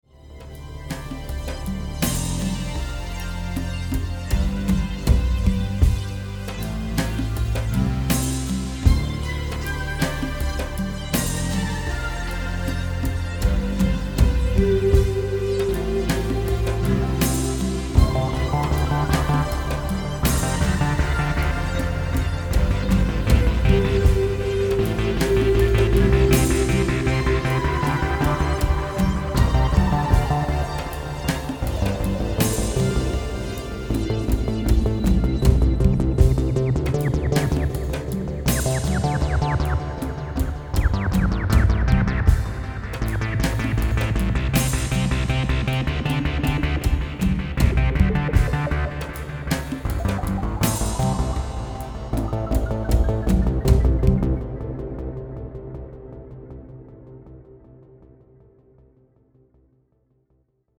Volt适用于Ambient，Abstract，Chill-Out和EDM等大多数类型的电子音乐作品。
Audio demos are made with just a few instances of Volt II using simple chords etc - Volt II does the rest with slow evolving textures and gritty bass and distorted arpeggios etc.